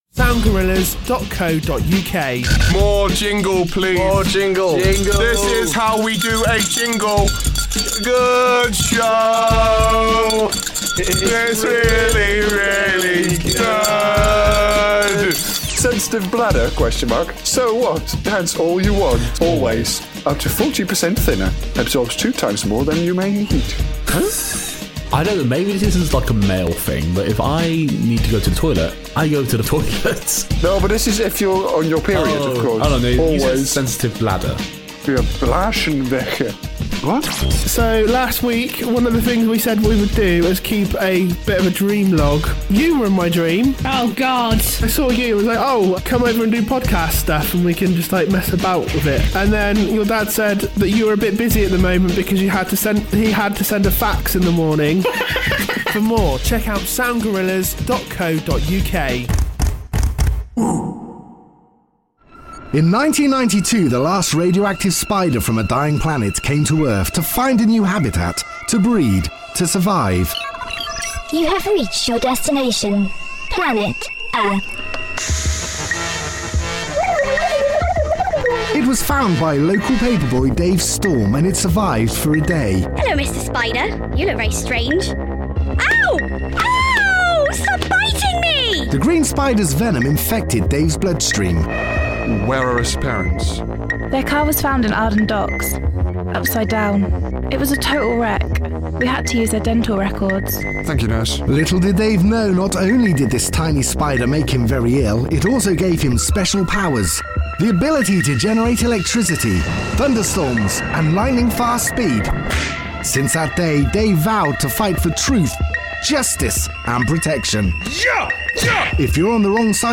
Code Red is an audio comic produced by us at Sound Gorillas in 2014.